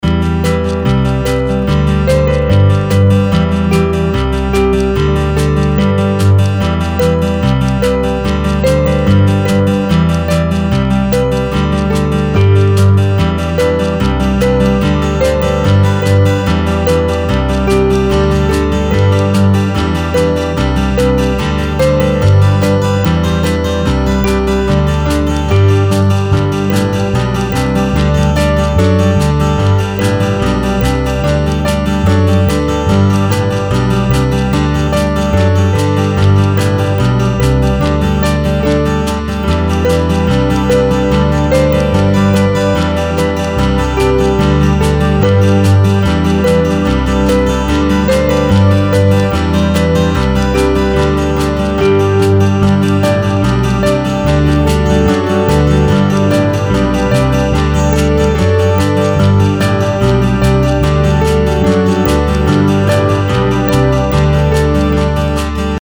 Uplifting Acoustic Loop